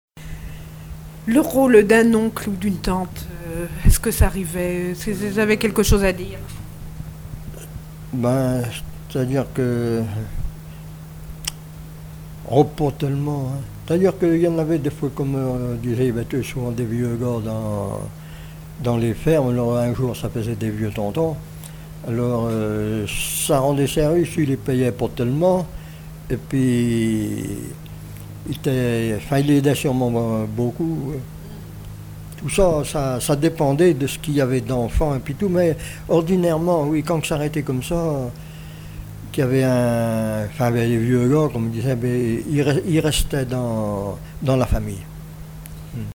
Témoignages de vie
Témoignage